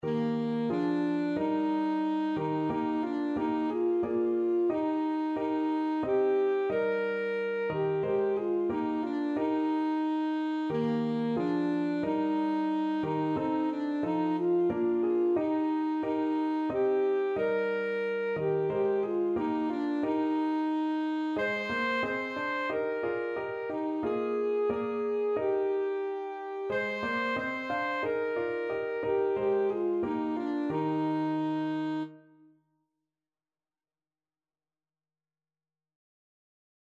Christmas Christmas Alto Saxophone Sheet Music Once in Royal David's City
Alto Saxophone
Eb major (Sounding Pitch) C major (Alto Saxophone in Eb) (View more Eb major Music for Saxophone )
4/4 (View more 4/4 Music)
Traditional (View more Traditional Saxophone Music)